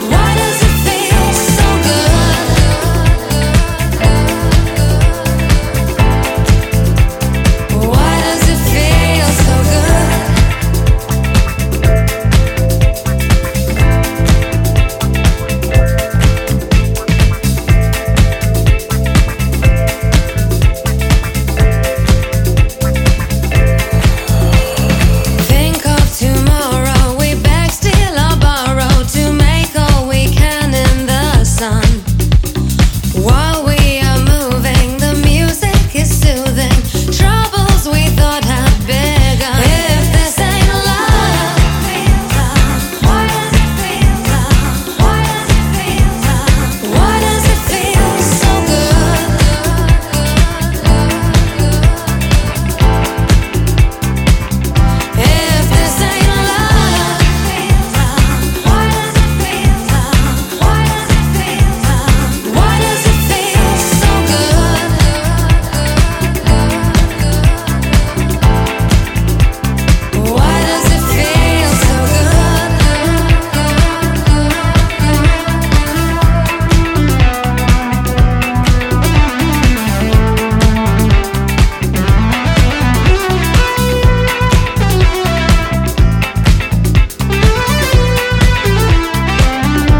ジャンル(スタイル) DISCO HOUSE / DEEP HOUSE / POP